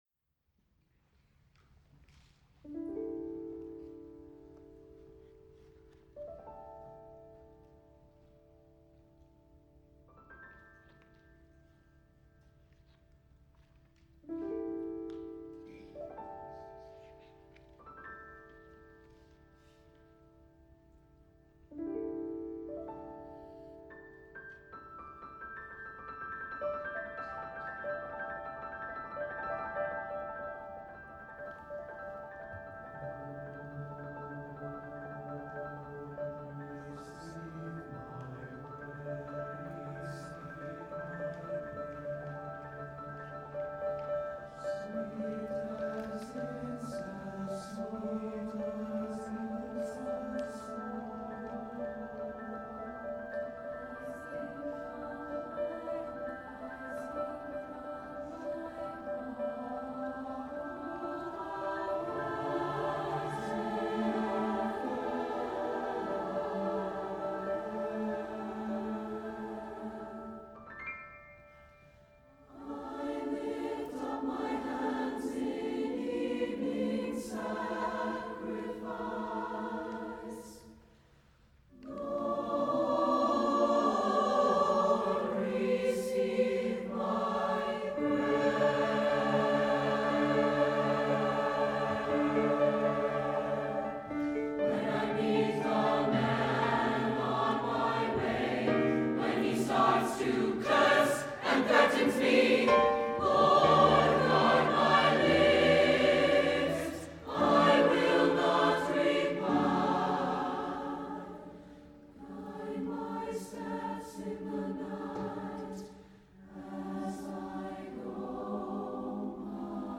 for SATB Chorus and Piano (2006)